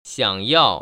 [xiăng yào] 시앙야오